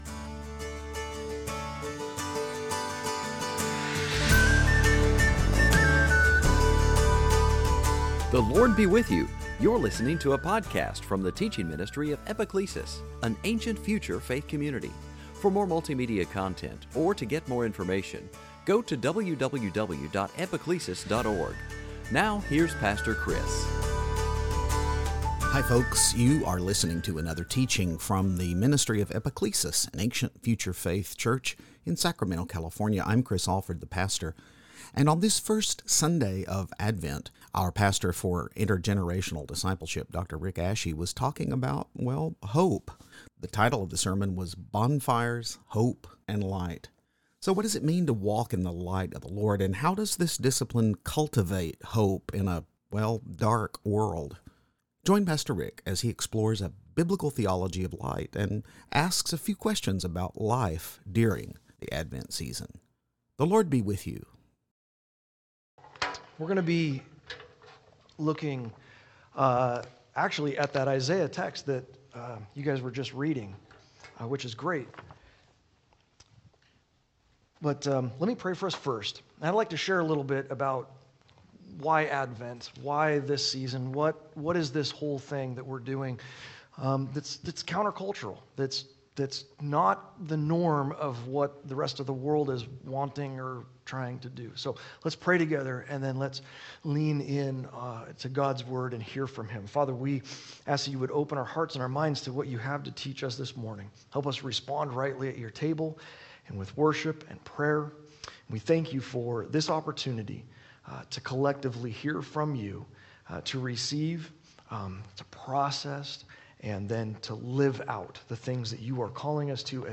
Join us for the first Sunday of Advent as we explore a biblical theology of light and ask a few questions about life during the Advent season.
2022 Sunday Teaching Advent bonfires hope Isaiah light walking in light Advent